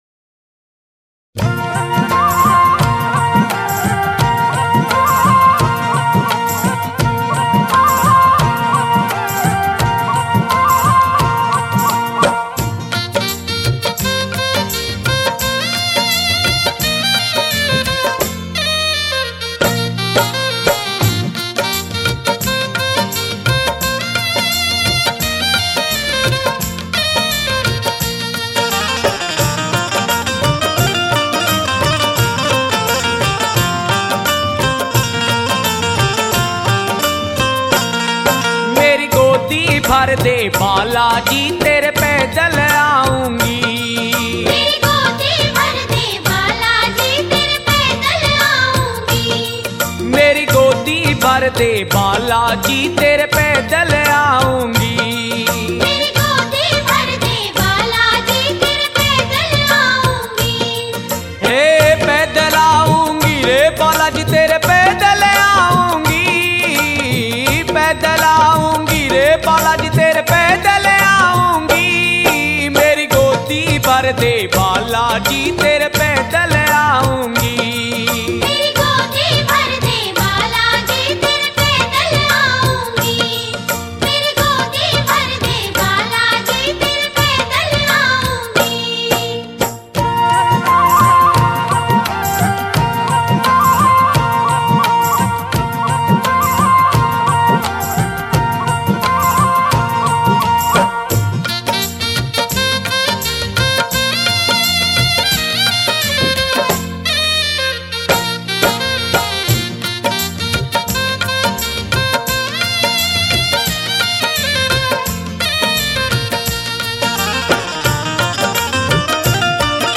Rajasthani Songs
Balaji Bhajan